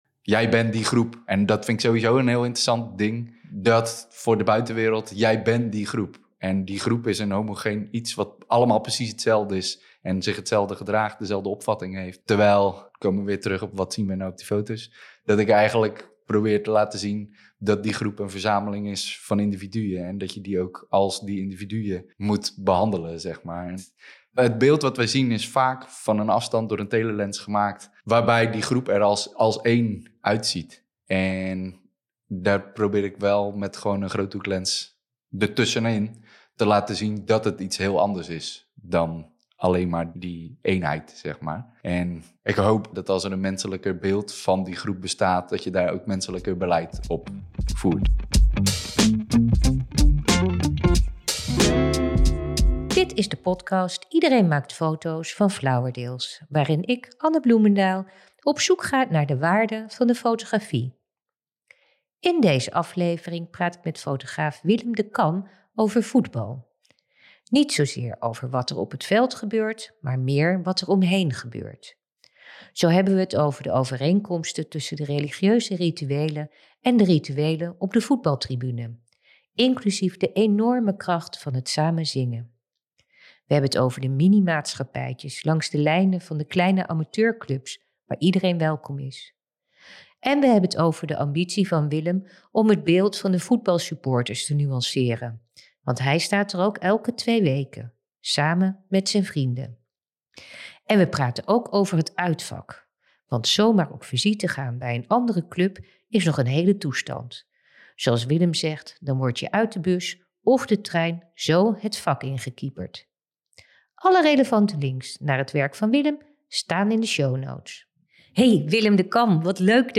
Dit gesprek is opgenomen op 2 december 2024 in Rotterdam.